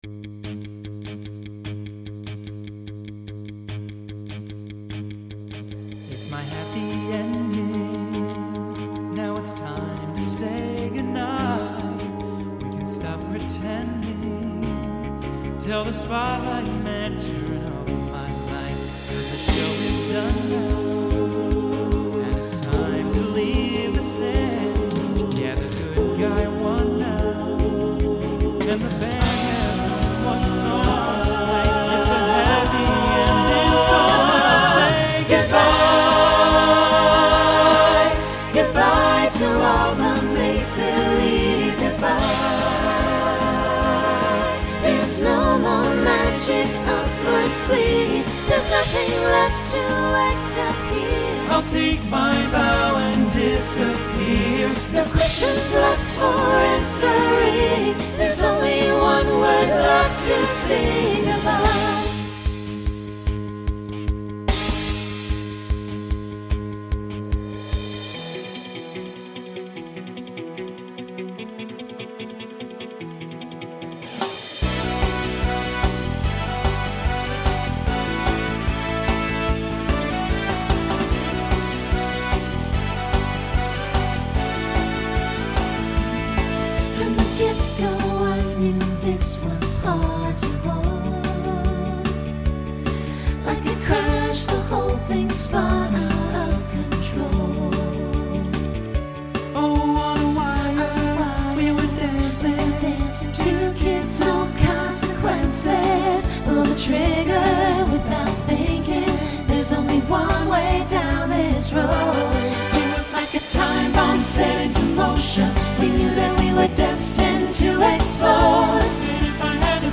Voicing SATB Instrumental combo
Broadway/Film , Rock